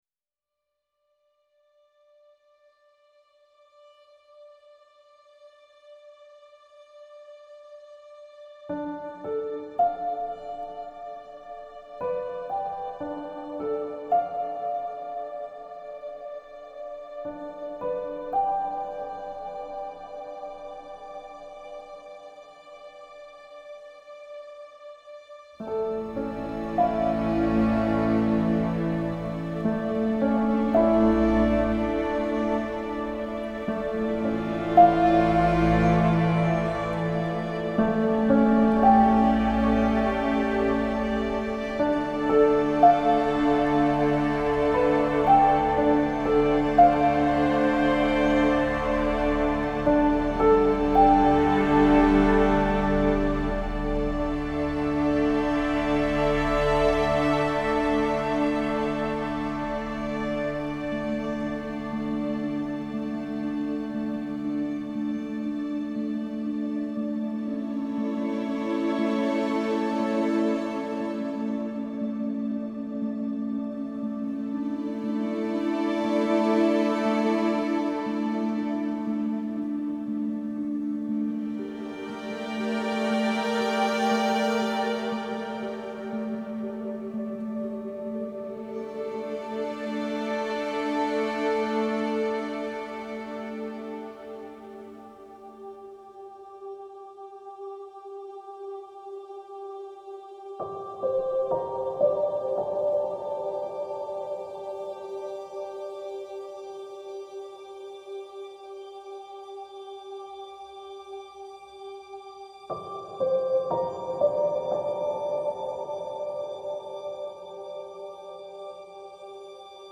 Genre : Film Soundtracks